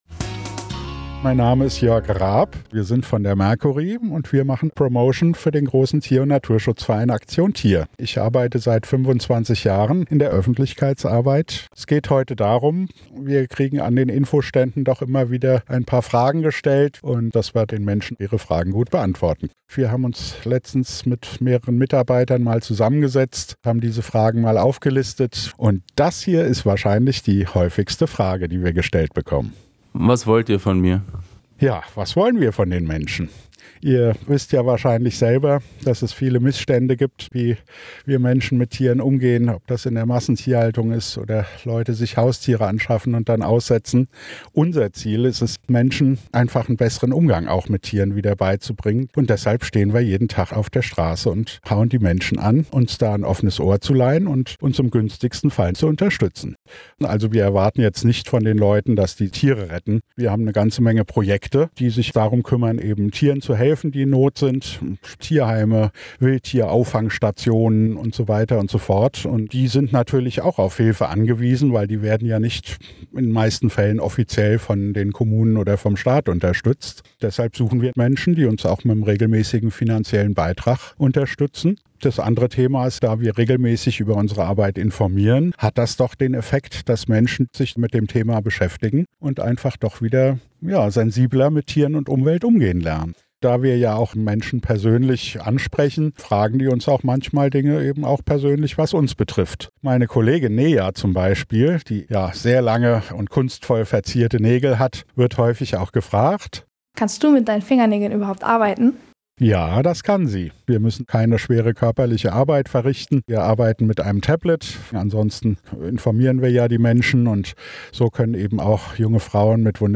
Was sie dabei schon alles erlebt haben, erzählen sie euch hier selbst.